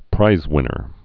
(prīzwĭnər)